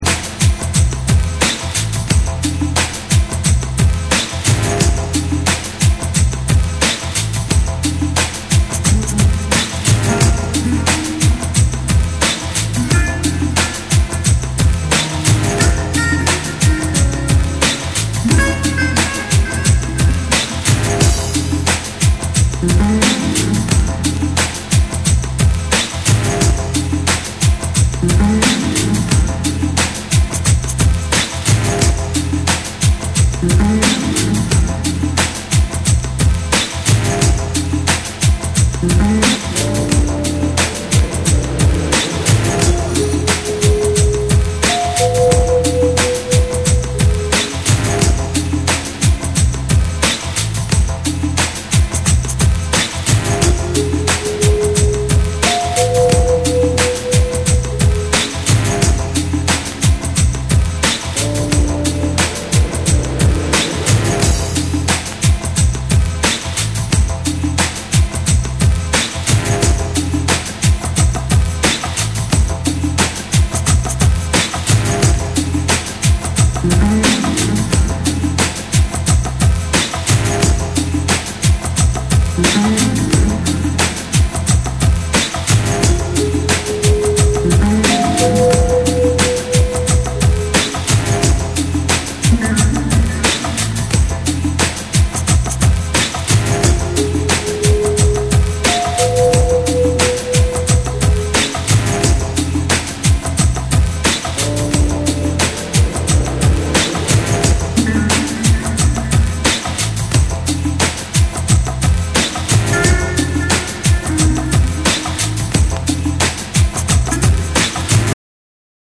BREAKBEATS